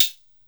606chat.wav